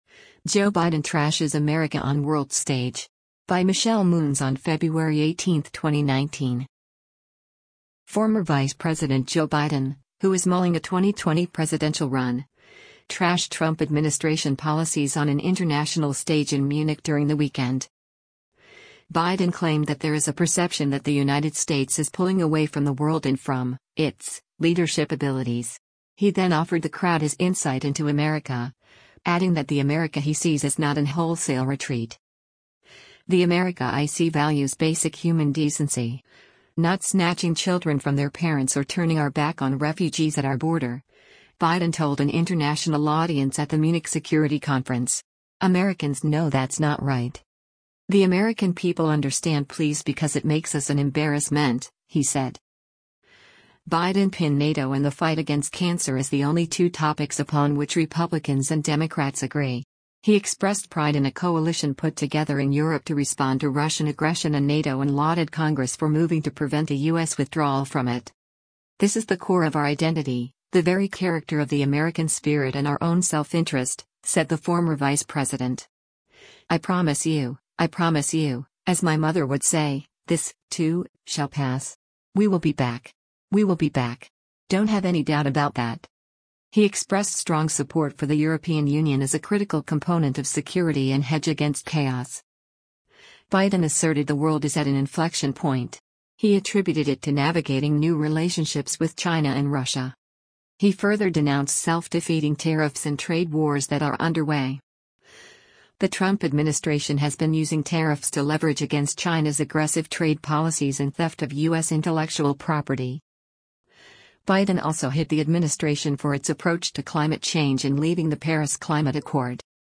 Former US Vice President Joe Biden gives a speech at the 55th Munich Security Conference i